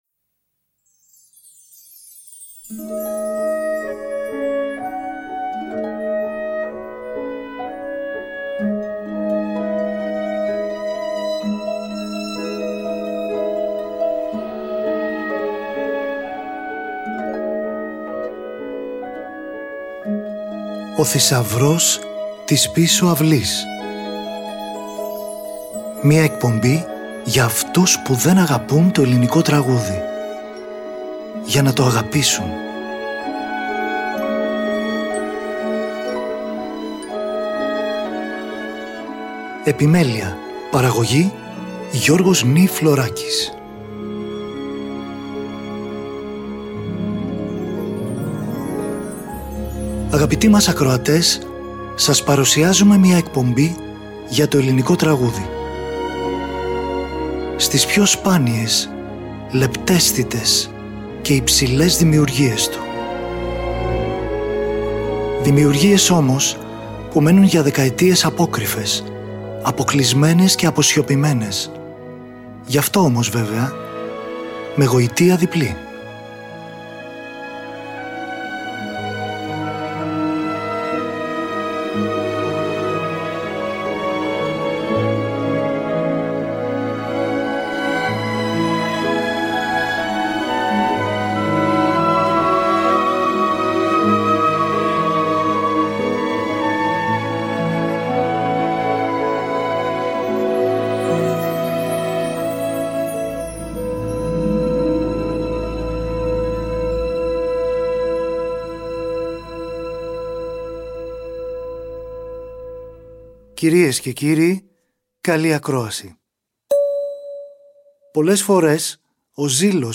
τραγούδια και ορχηστρικά